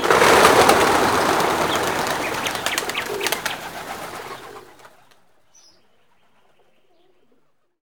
WING FLUT00R.wav